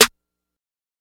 SB6 Snare (1).wav